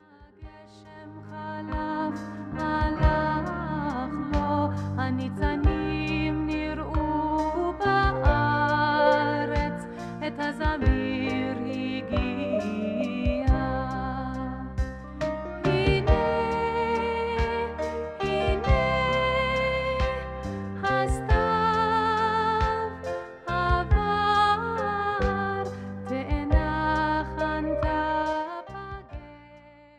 (Folk)